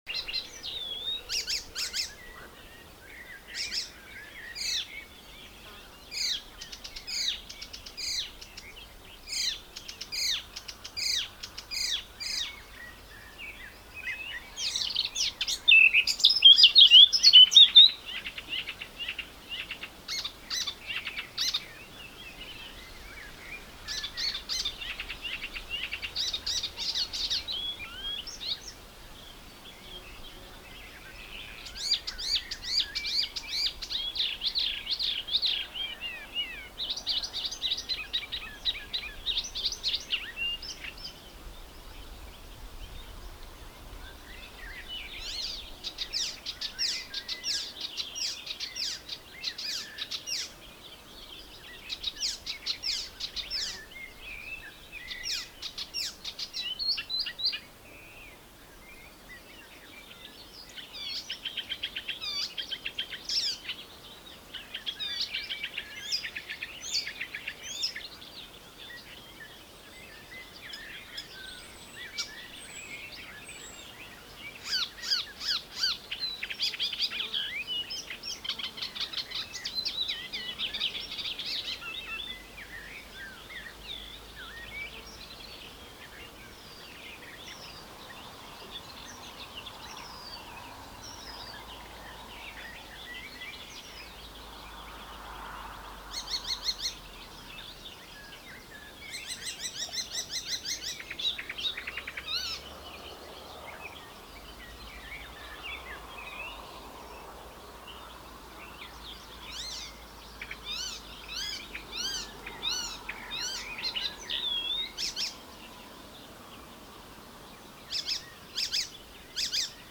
Wél vond ik eindelijk mijn gewenste Spotvogel in het struweel naast het fietspad. Ik zag hem niet maar omdat hij hier zo mooi zat te zingen, in een veel rustiger omgeving dan toen in St Maartenszee, heb ik een paar minuutjes staan filmen om zijn zang in ieder geval weer vast te leggen.
De heerlijke Spotvogel!
SpotvogelMP3.mp3